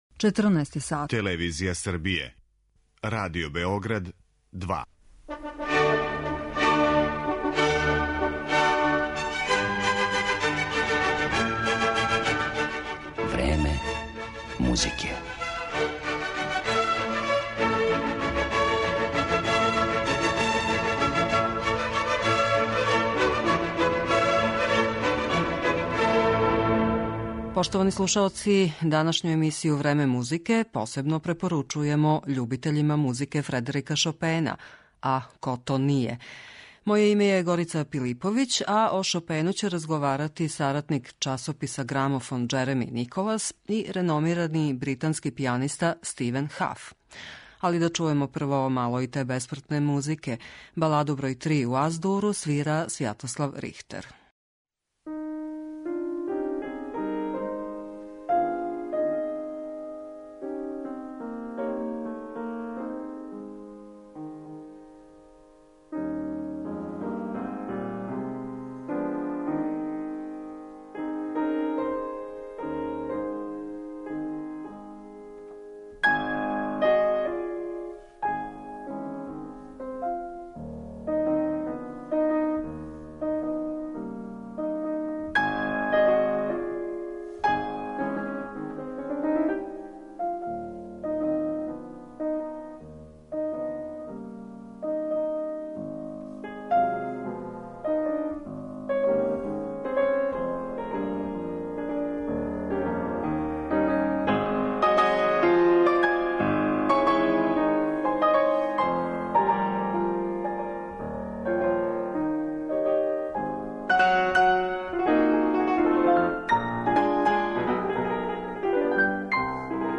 Разговор о Шопену